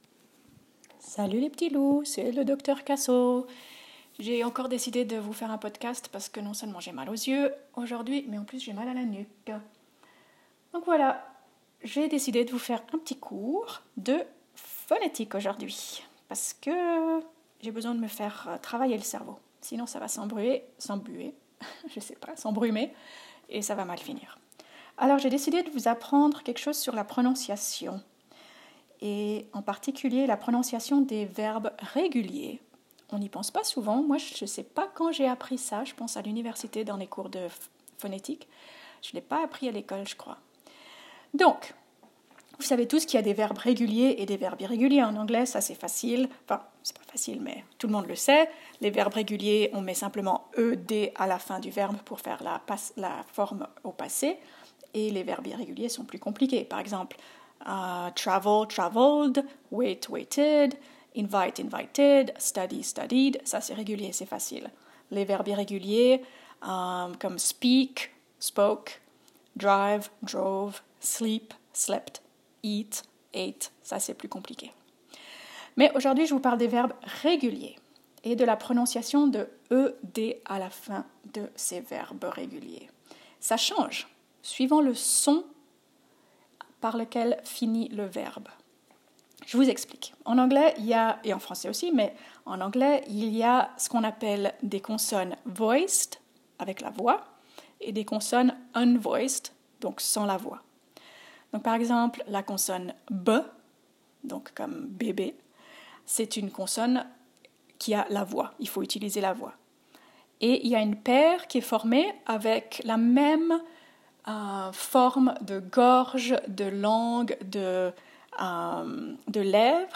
Je n’en peux plus des discussions sur le merdier dans lequel on est, alors je vous propose un petit cours de phonétique très facile mais très utile, de six minutes seulement 🙂
Je remarque que tu ne prononce pas “ate” comme moi : je le prononce court, [et] et non [eit].